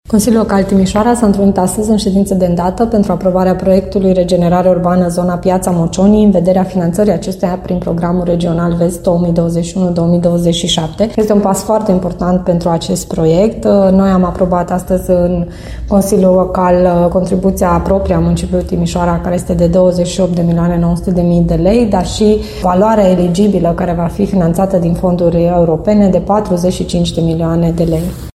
Viceprimarul Paula Romocean precizează că investiția presupune o contribuție locală, necesară pentru implementarea proiectului.